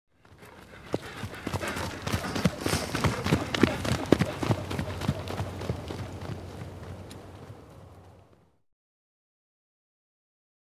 جلوه های صوتی
دانلود صدای اسب 57 از ساعد نیوز با لینک مستقیم و کیفیت بالا
برچسب: دانلود آهنگ های افکت صوتی انسان و موجودات زنده دانلود آلبوم انواع صدای شیهه اسب از افکت صوتی انسان و موجودات زنده